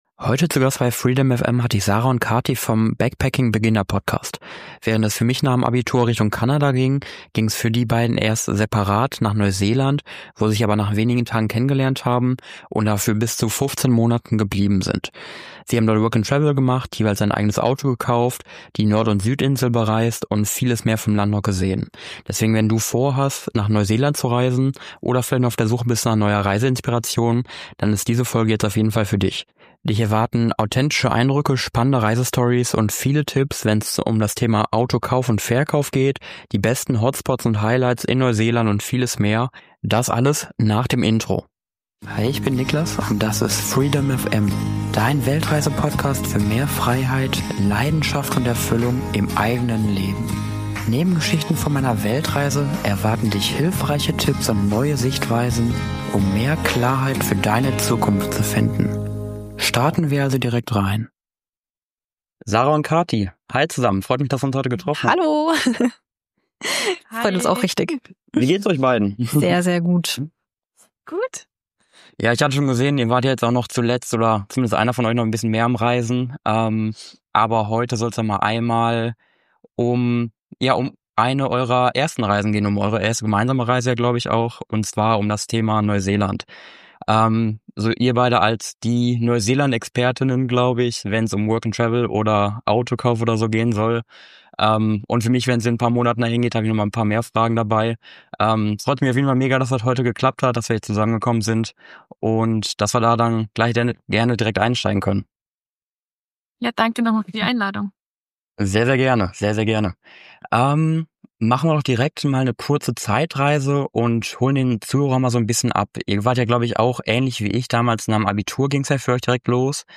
FFM.89 - 15 Monate Work & Travel in NEUSEELAND - Interview